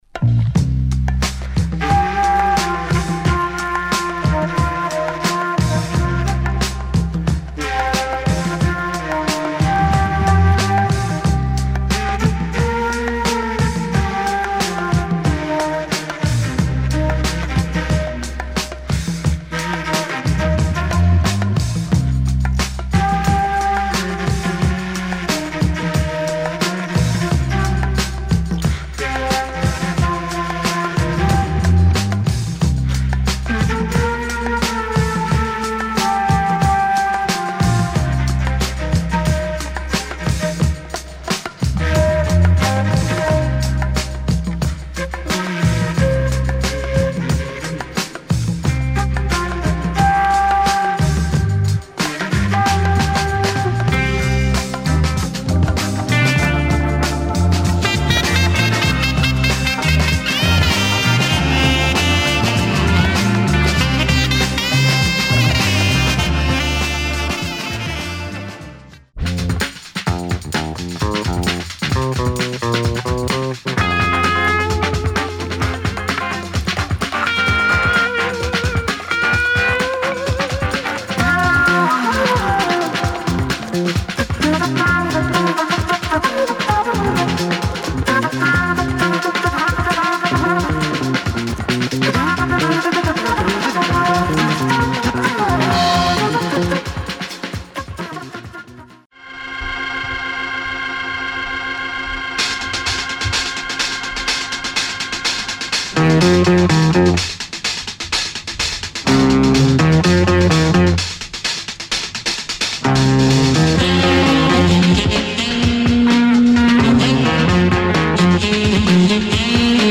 cult buzz flute groove